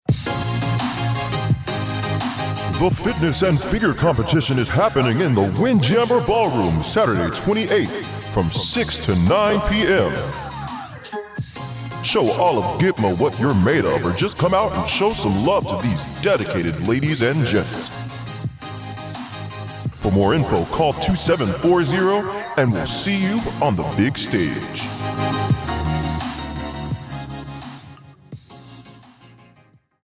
A radio spot informing Naval Station Guantanamo Bay residents of the Fitness and Figure Competition